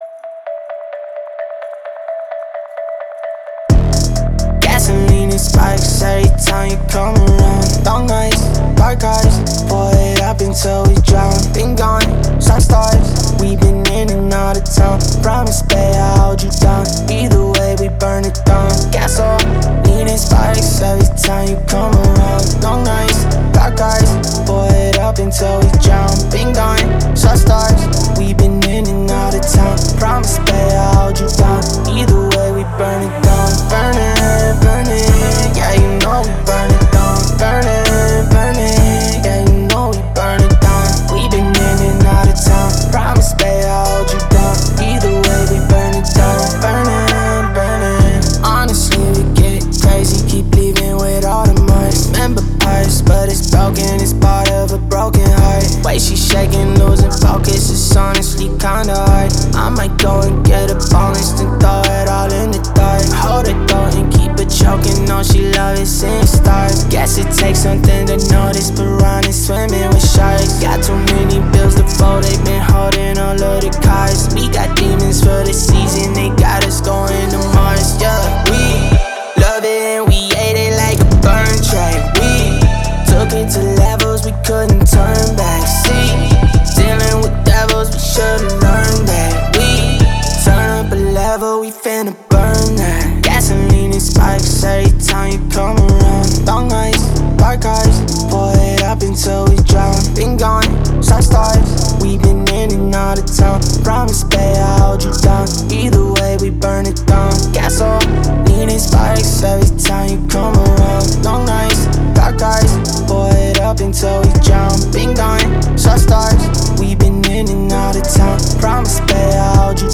который сочетает в себе элементы R&B и соула.